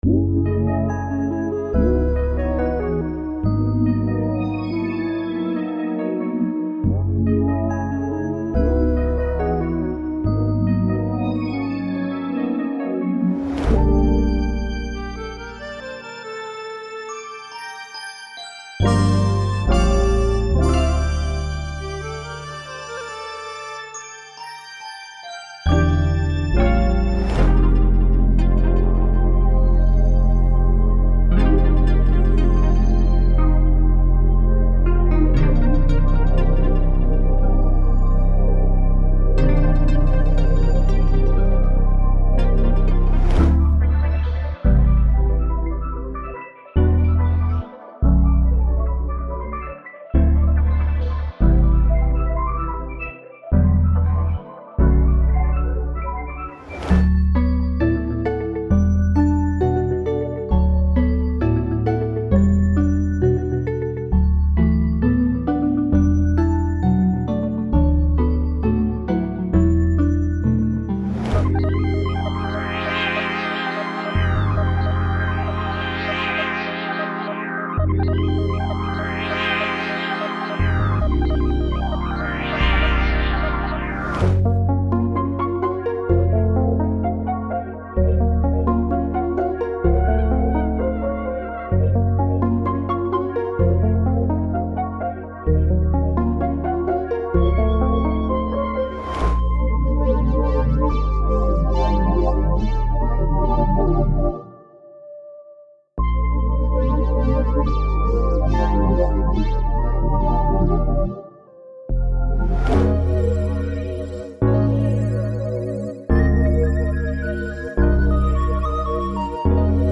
From wavy keys to ambient pads, funky leads to transcendent bells, this pack is a must have for the modern producer
• 13 Pads
• 7 Bells/Mallets